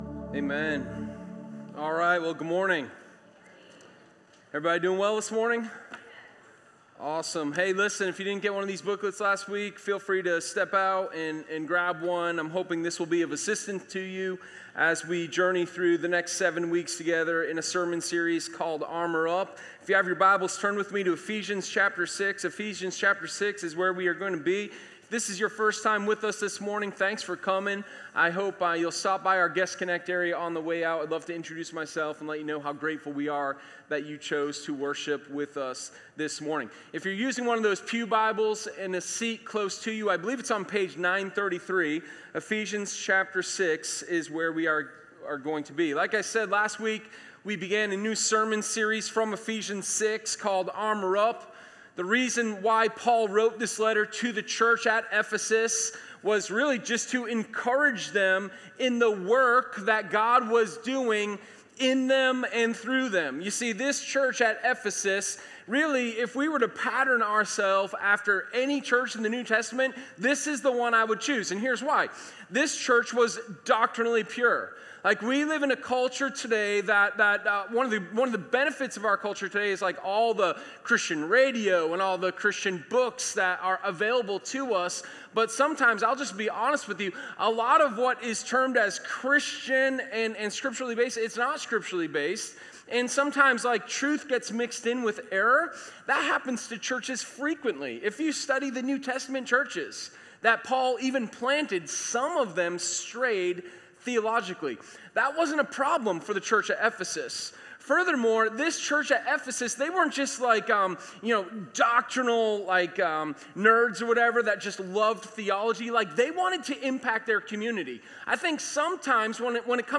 Sermon01_24_The-Belt-of-Truth.m4a